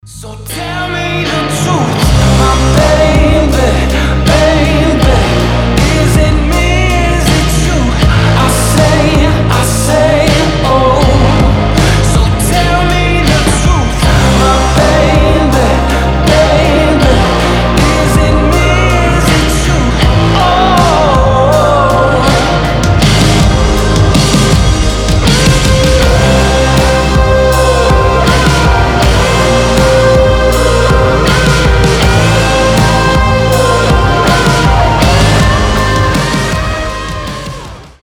alternative
indie rock